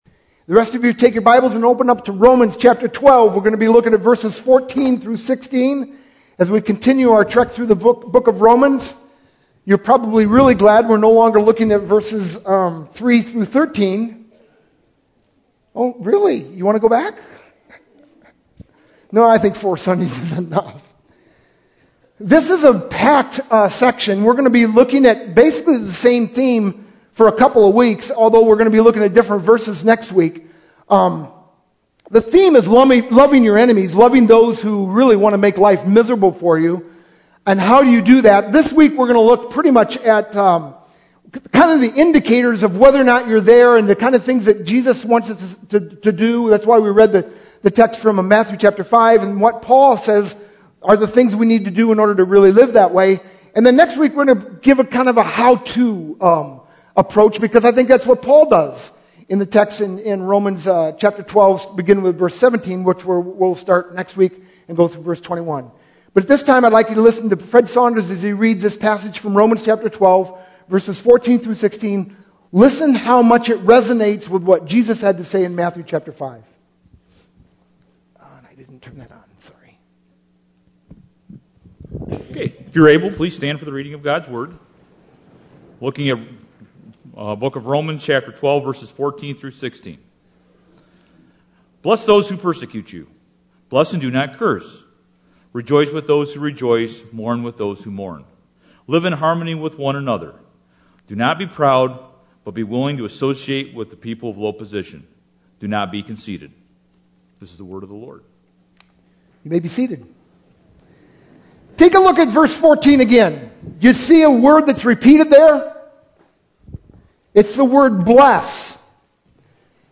sermon-1-1-12.mp3